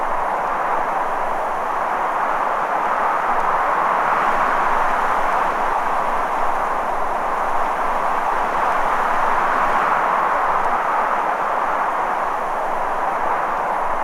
Wind_Heavy.ogg